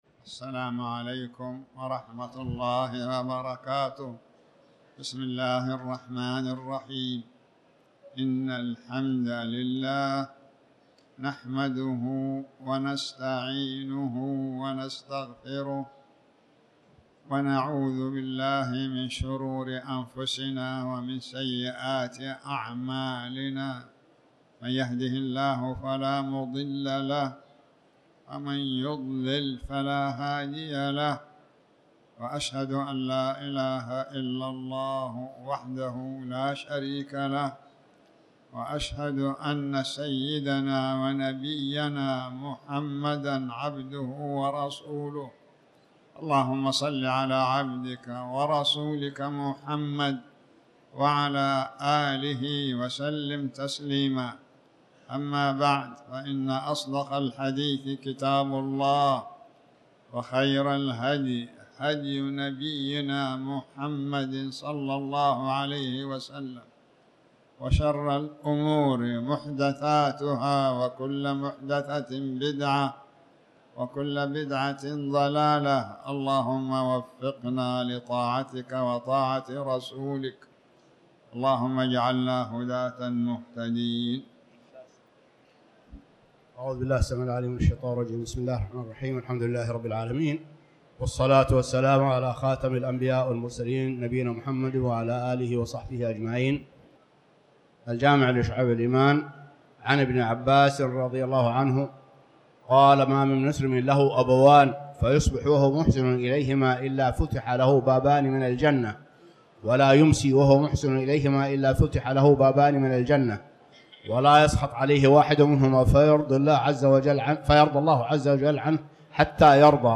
تاريخ النشر ٢ ربيع الثاني ١٤٤٠ هـ المكان: المسجد الحرام الشيخ